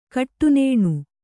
♪ kaṭṭunēṇu